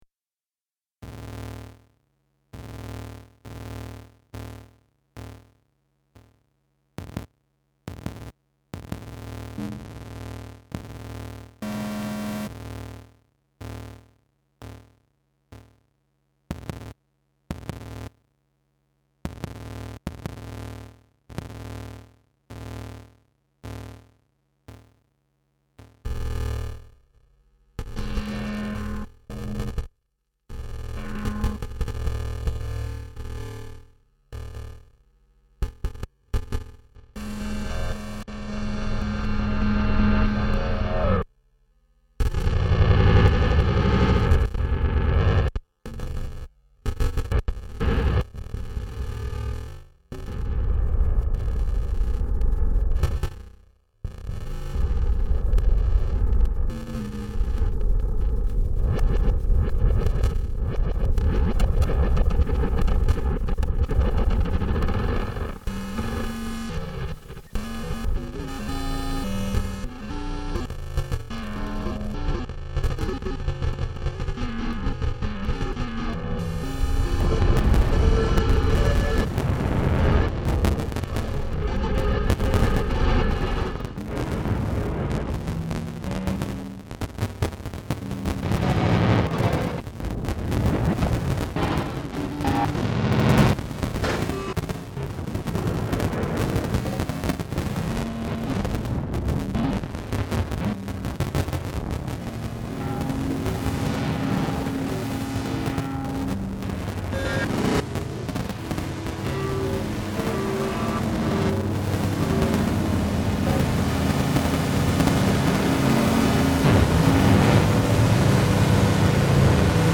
I recorded a loop of synthesized sounds that seemed to go with the sounds on the show, and then I came up with a cutup algorithm that broke it into pieces based on a 9-feeling. But that wasn’t enough, so I added in some glitching.
Then I spent the summer mixing it down to 2 channels, while trying to prevent it from getting muddy. I won’t lie, it sounds better with more channels, but the stereo version is ok.